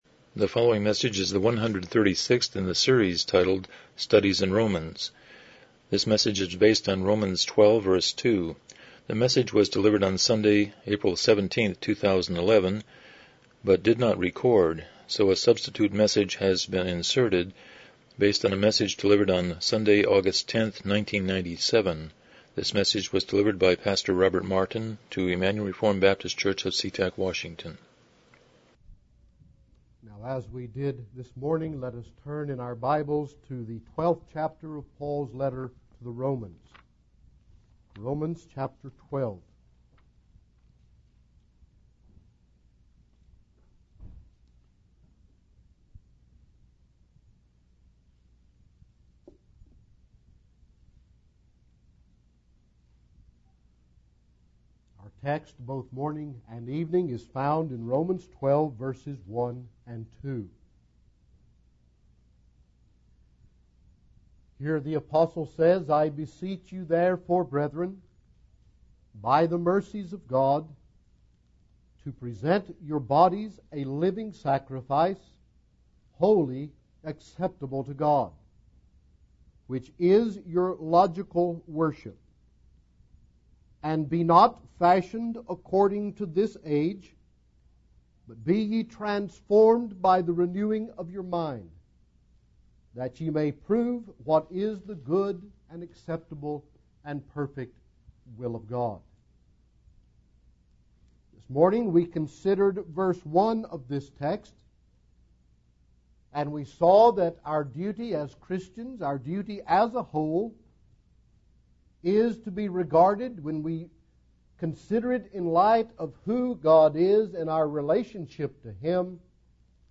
Romans 12:2 Service Type: Morning Worship « 26 Philippians 4:10-23